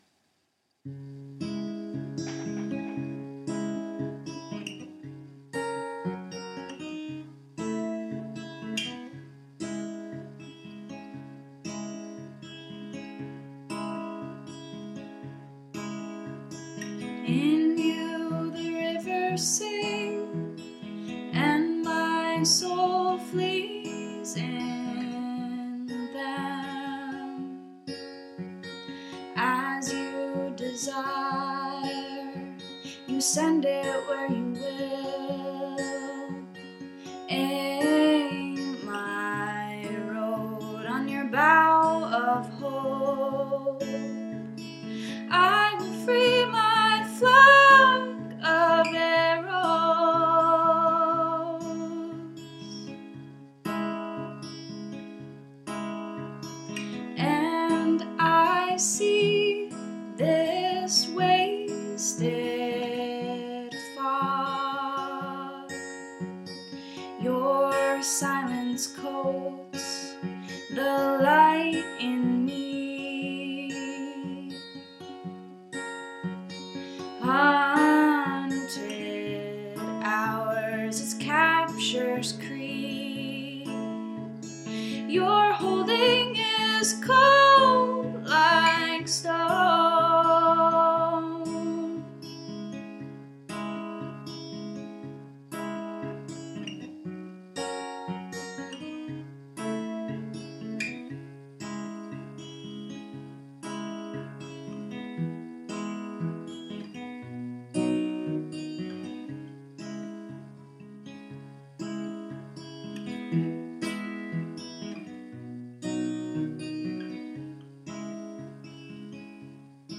(Recorded in an empty living room.)